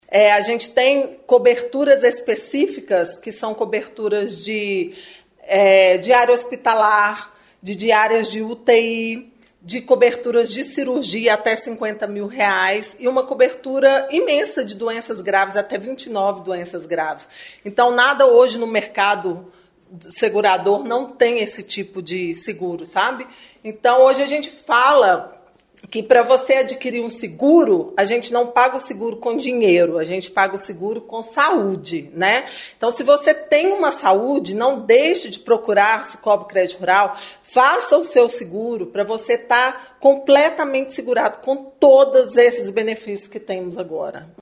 Falando ao Jornal da Manhã, ela mostrou as vantagens do seguro: